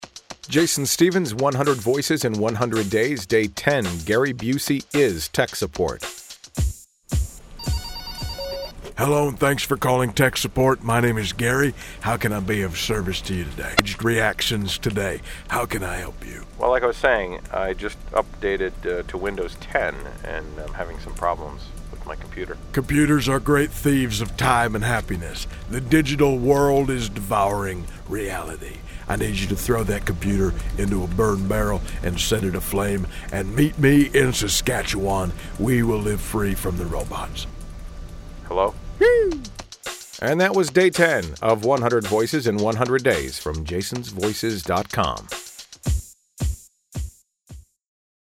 Tags: Gary Busey impression